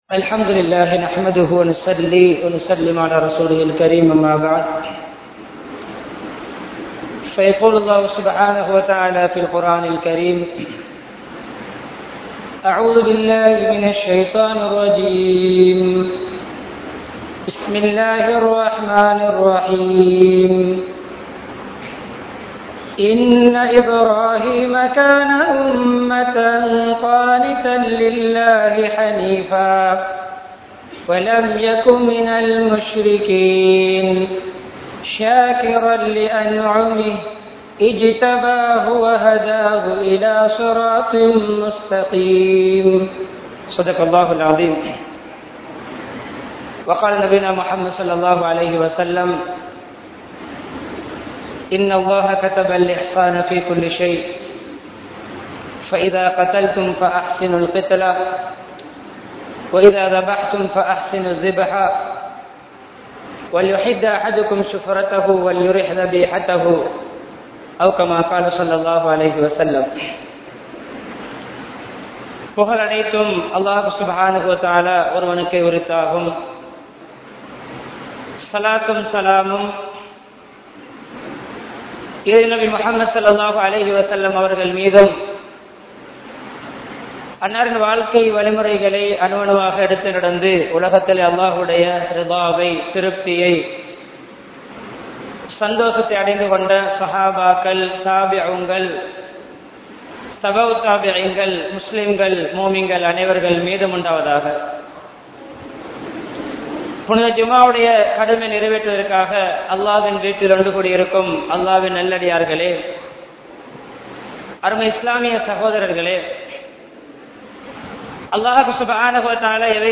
Eidul Alhavum Miruha Urimaihalum (ஈதுல் அல்ஹாவும் மிருக உரிமைகளும்) | Audio Bayans | All Ceylon Muslim Youth Community | Addalaichenai